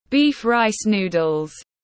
Bún bò tiếng anh gọi là beef rice noodles, phiên âm tiếng anh đọc là /biːf raɪs nuː.dəl/
Beef rice noodles /biːf raɪs nuː.dəl/